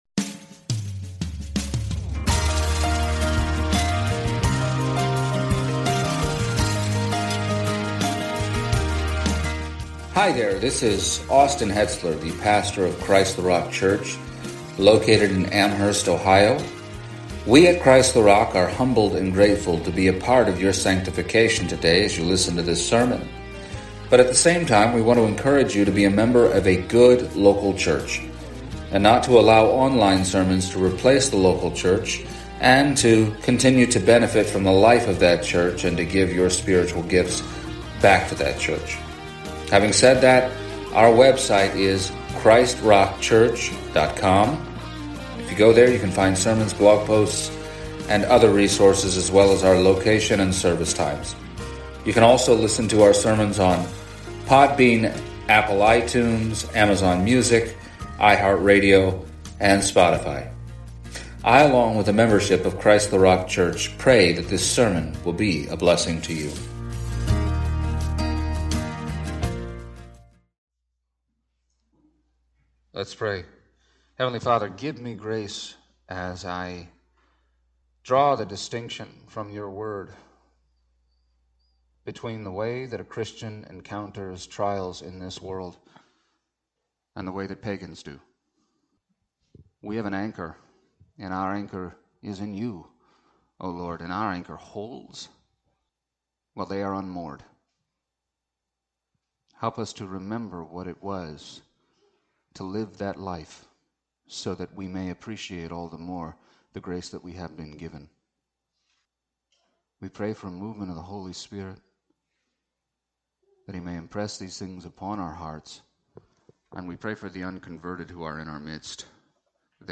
Passage: Acts 27:13-44 Service Type: Sunday Morning